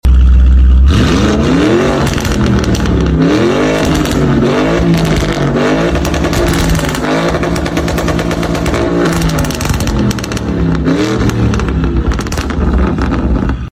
One of our favorite Capristo Exhaust installs is the Lamborghini Urus! Such a powerful sound 💥 One Of Our Favorite Capristo Sound Effects Free Download.